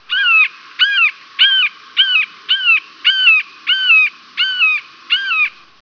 This morning at about 5AM a Red Shouldered Hawk decided to perch himself right outside my bedroom window and proceeded to call...LOUDLY!
For those of you who have never heard the call of a Red Shouldered Hawk here is what one sounds like.
Red Shouldered Hawk Just imagine that non stop for 30 minutes.